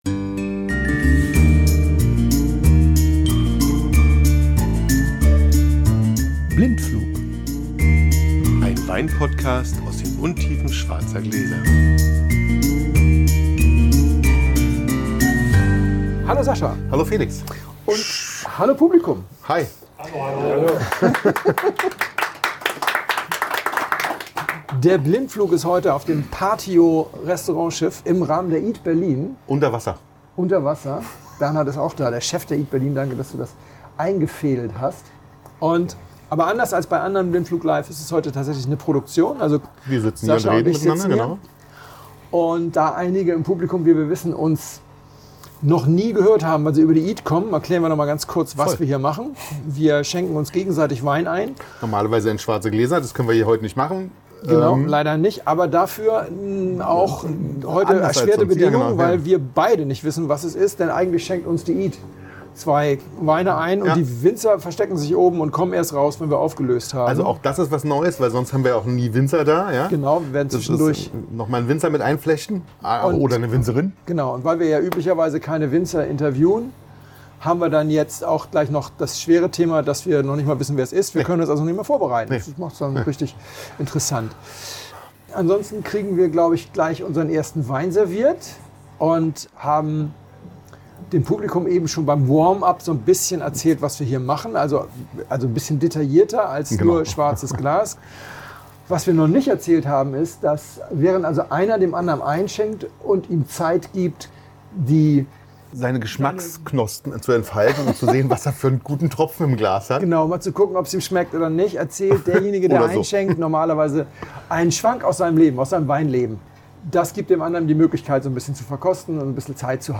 Der Blindflug gastiert auf dem Gourmetfestival.
Hört selbst, mit ein paar mehr Nebengeräuschen als üblich, aber doch gut verständlich.